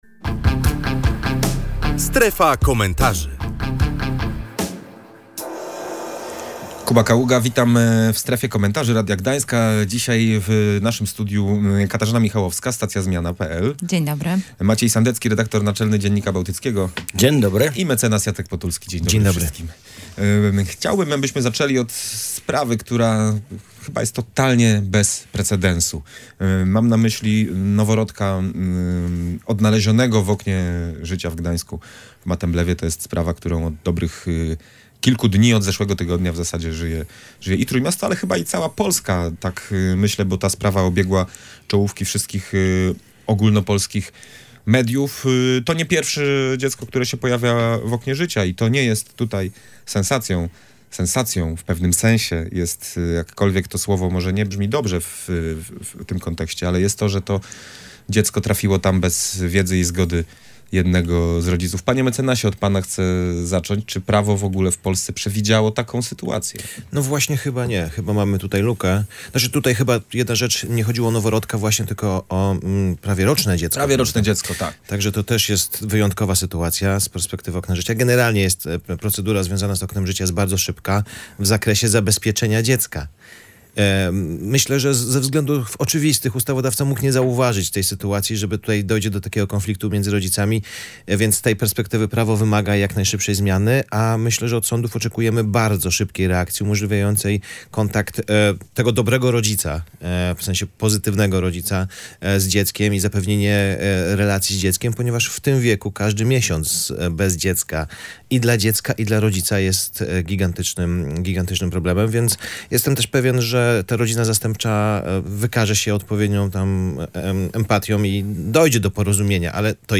W „Strefie Komentarzy” rozmawialiśmy o sprawie bez precedensu – noworodku znalezionym w oknie życia w Gdańsku Matemblewie.